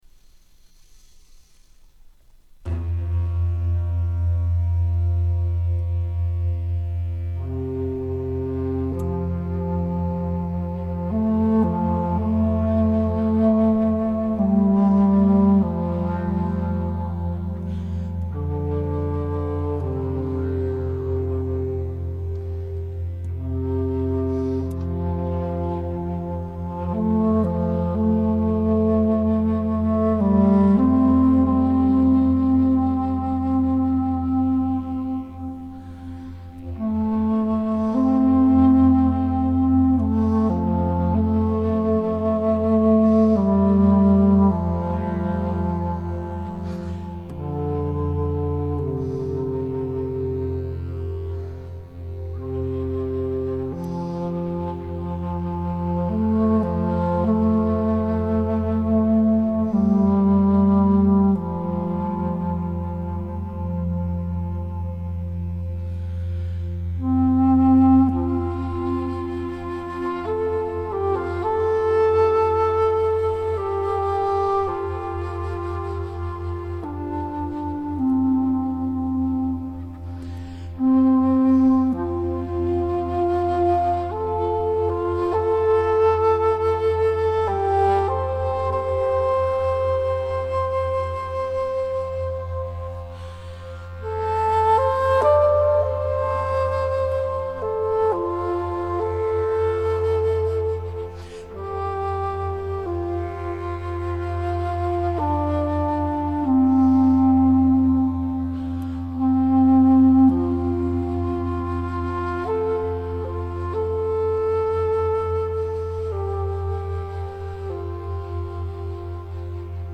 这首长笛曲的绝美程度，实在是文字难以企及，这是一种源自心底的颂赞。